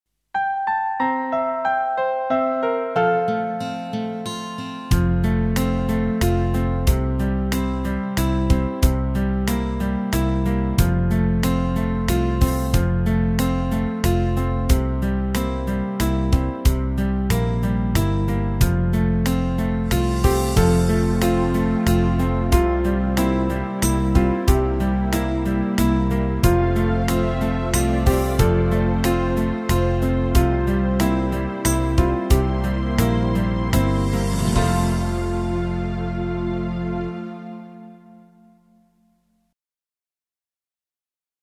Meespeel CD